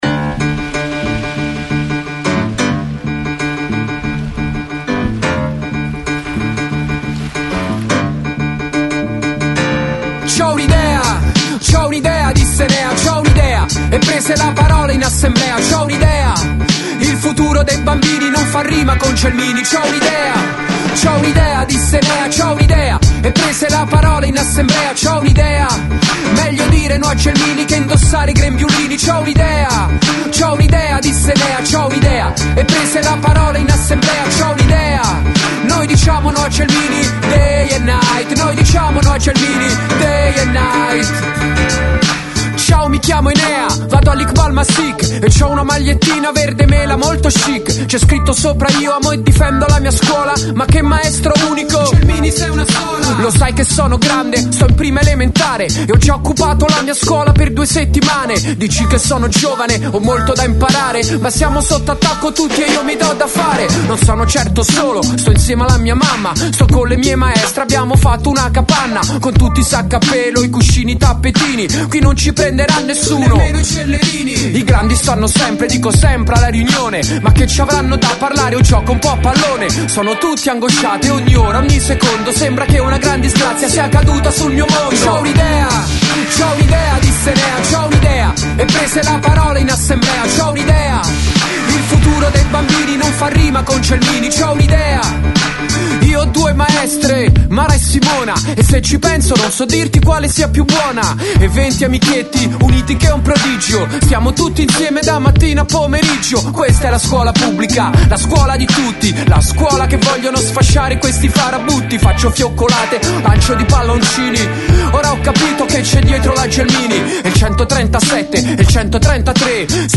Il rap